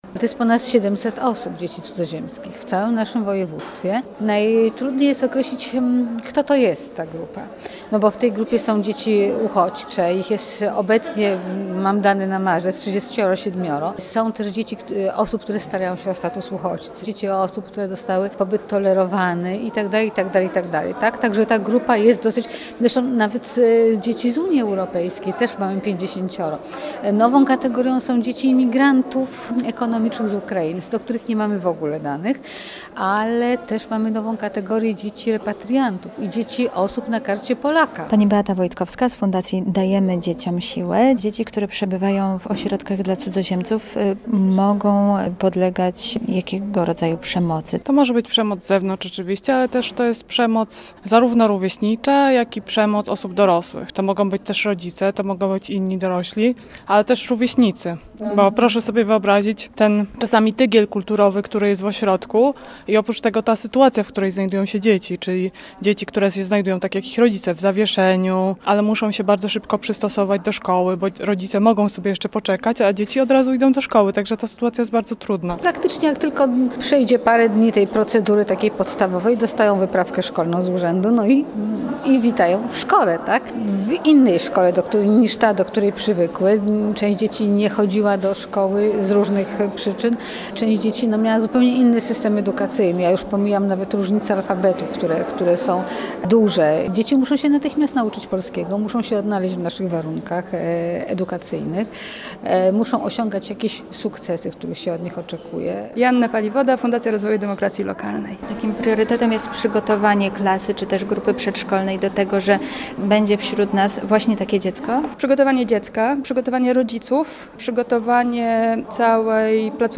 Posłuchaj relacji: Nazwa Plik Autor Dzieci cudzoziemskie audio (m4a) audio (oga) Warto przeczytać Kolejny transfer Widzewa!